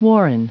Prononciation du mot warren en anglais (fichier audio)
Prononciation du mot : warren
warren.wav